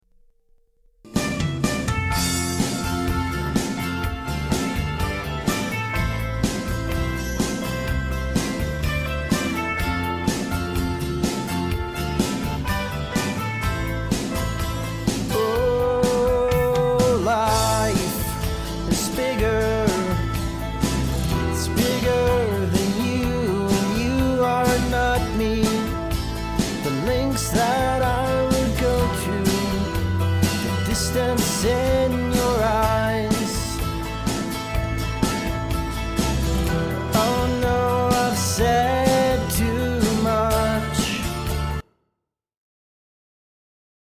This isn't a mix at all, just my voice over karaoke track.
This was recorded on a mobile pre with an at2035 .